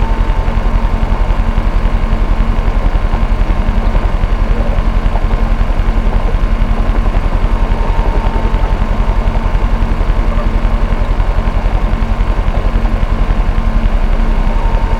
Sfx_tool_spypenguin_idle_interior_damage_01.ogg